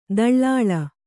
♪ daḷḷāḷa